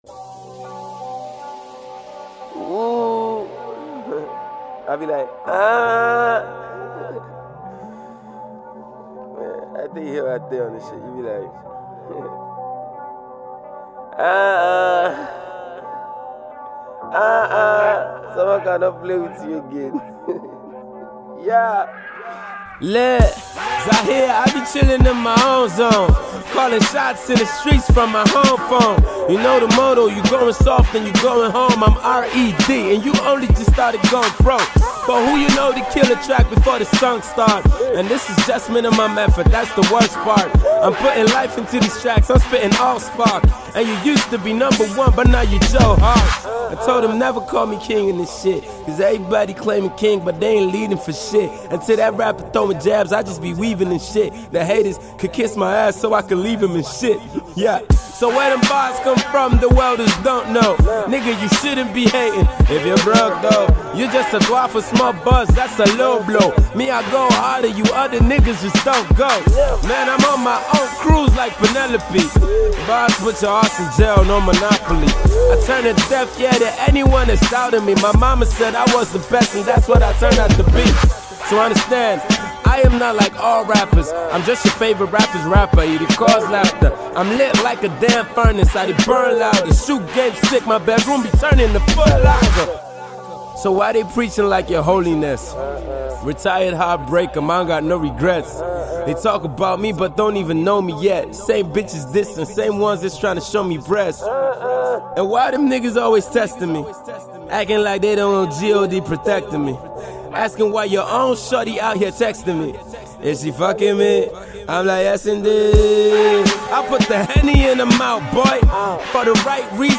Naija Music
Dope punchlines.